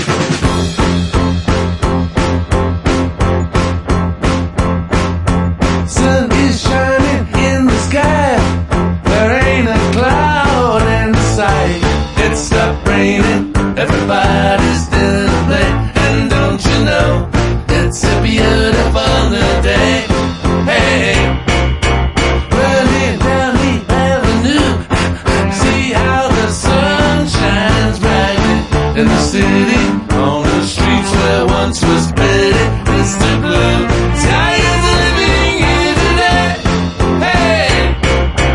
English Rock Band